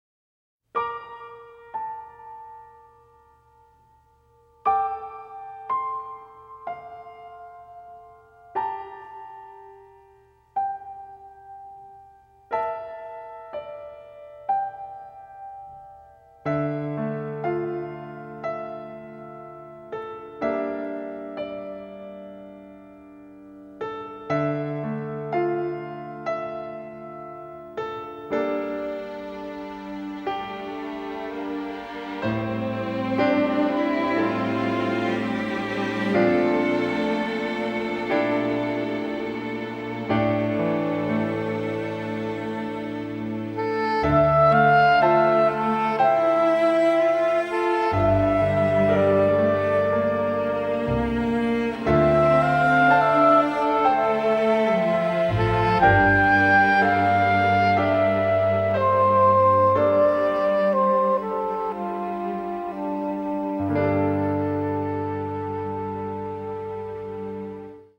a bubbly score featuring lush orchestrations